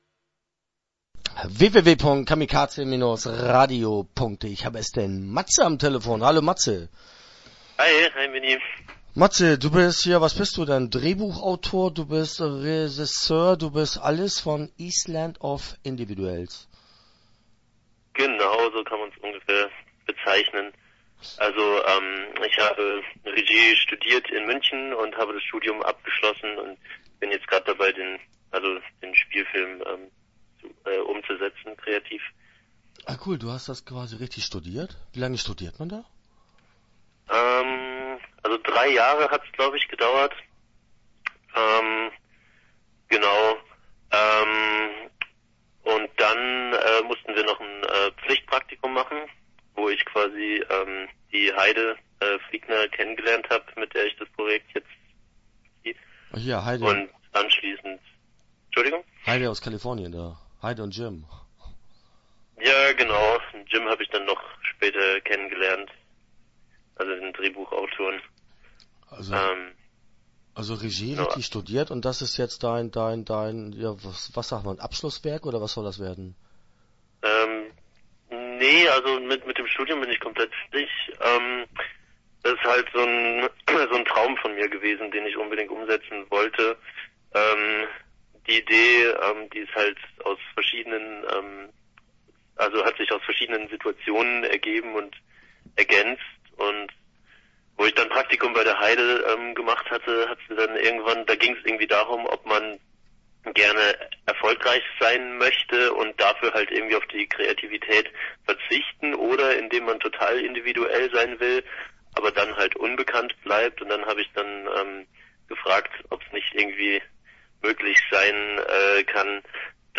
Extinct - Island of Individuals - Interview Teil 1 (12:06)